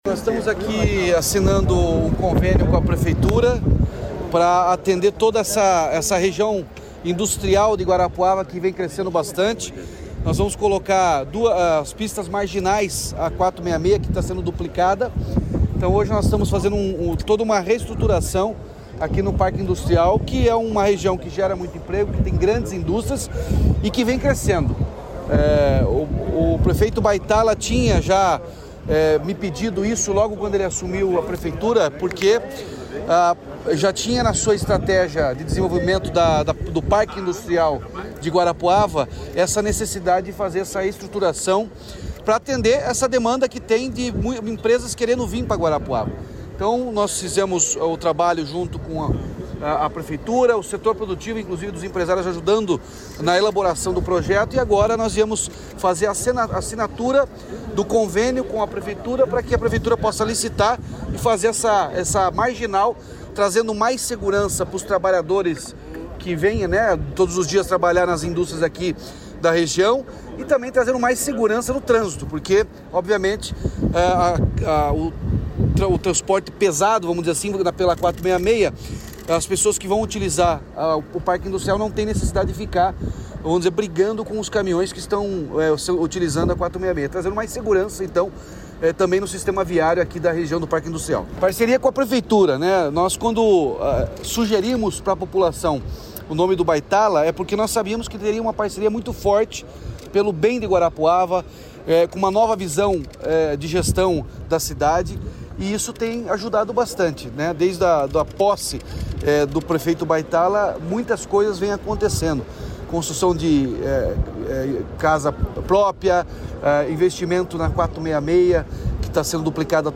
Sonora do governador Ratinho Junior sobre as novas obras de infraestrutura para o polo industrial de Guarapuava